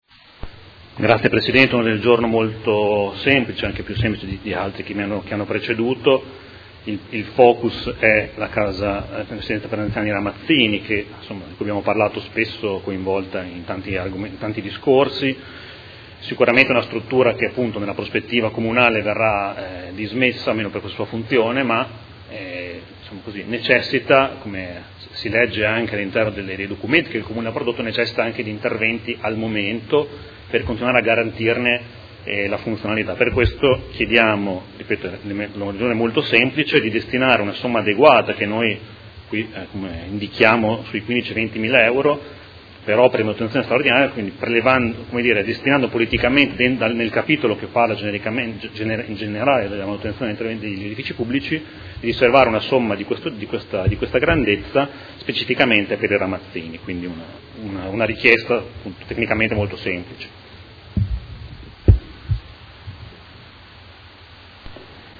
Seduta del 20/12/2018. Presenta Ordine del Giorno Prot. Gen. 207664